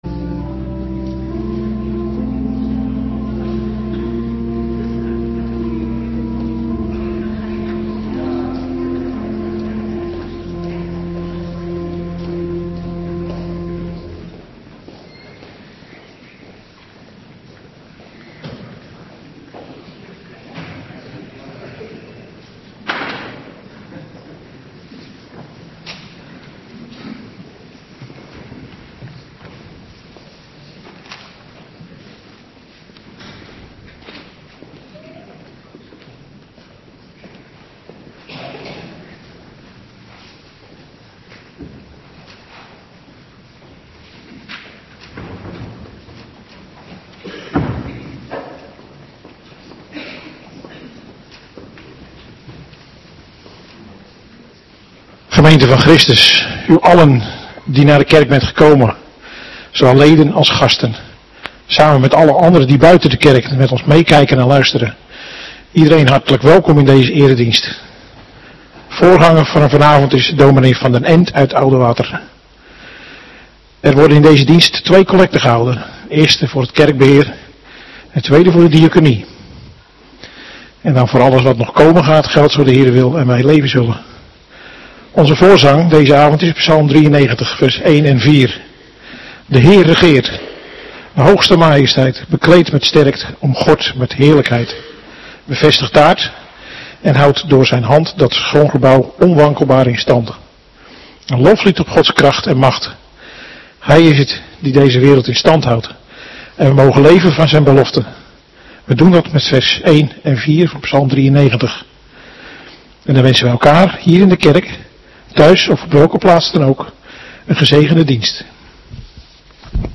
Avonddienst 28 december 2025